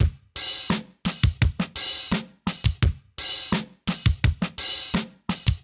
Index of /musicradar/sampled-funk-soul-samples/85bpm/Beats
SSF_DrumsProc2_85-02.wav